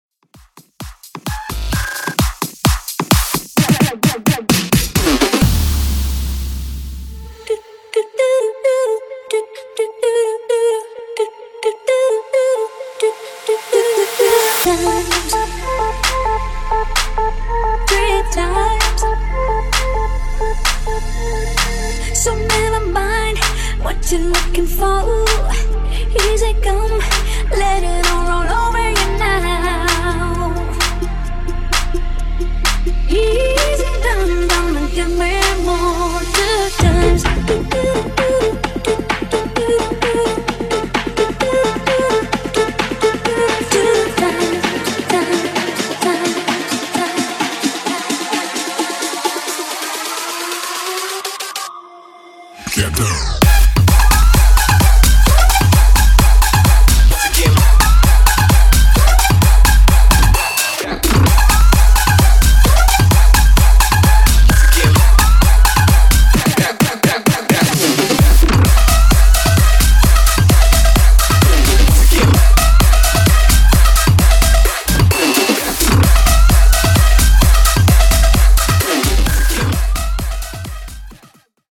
mashup 14 Genre: MASHUPS Version: Clean BPM: 130 Time